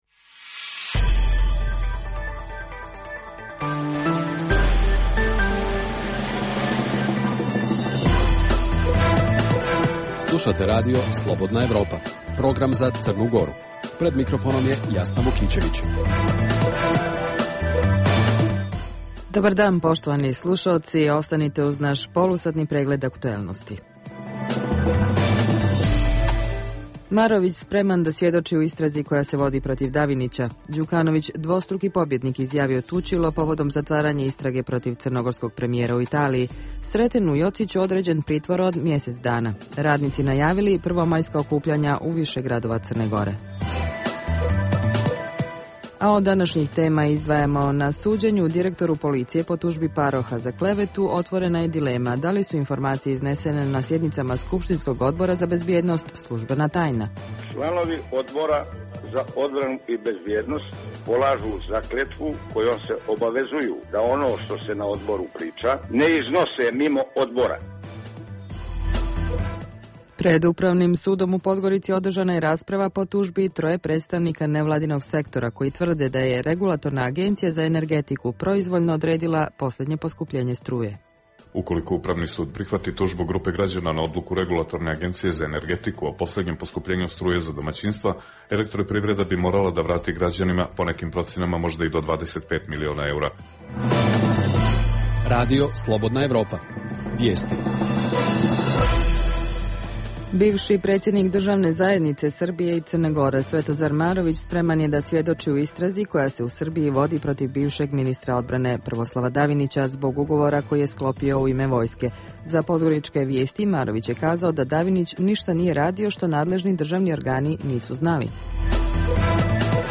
Emisija namijenjena slušaocima u Crnoj Gori. Sadrži lokalne, regionalne i vijesti iz svijeta, tematske priloge o aktuelnim dešavanjima iz oblasti politike, ekonomije i slično, te priče iz svakodnevnog života ljudi, kao i priloge iz svijeta.